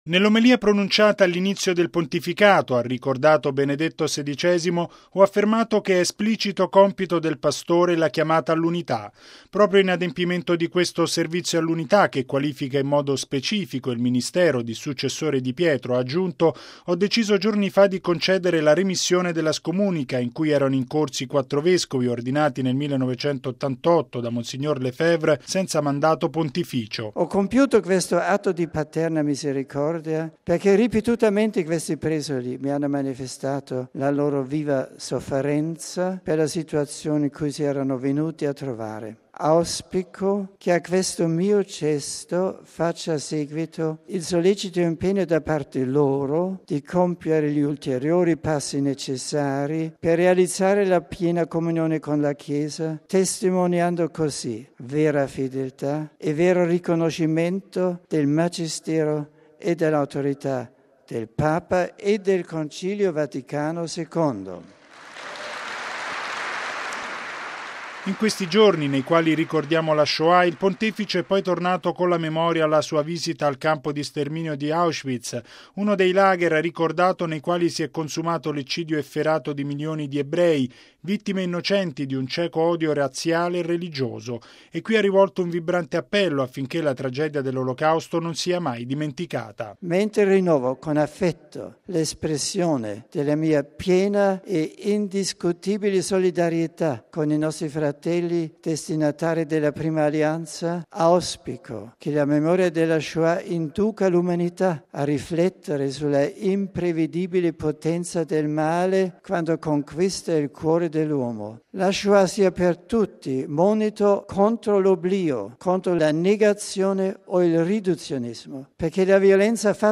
Una condanna categorica di ogni tesi negazionista dell’Olocausto è stata pronunciata stamani da Benedetto XVI all’udienza generale in Aula Paolo VI. Il Papa oltre a soffermarsi sulla Shoah, di cui si è celebrata ieri la Giornata internazionale della memoria, ha spiegato il significato della revoca della scomunica ai 4 vescovi della Fraternità San Pio X, esortandoli ad essere fedeli al Concilio Vaticano II.
(applausi)